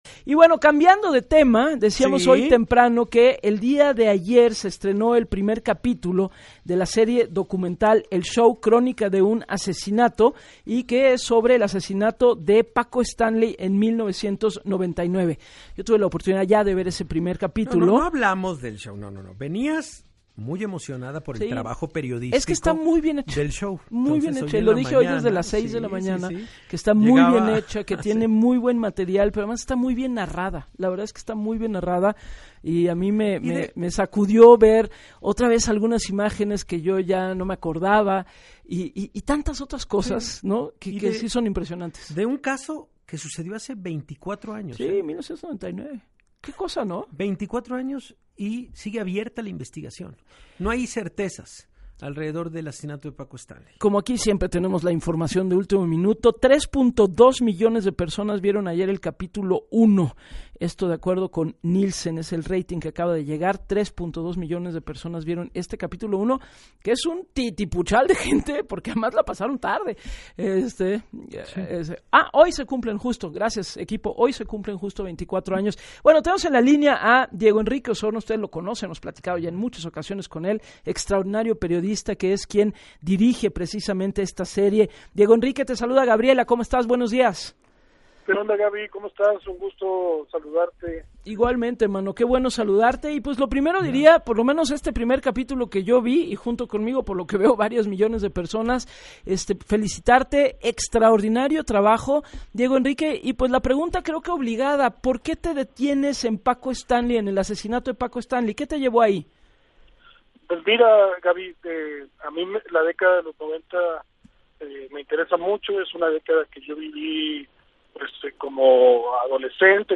Diego Enrique Osorno, periodista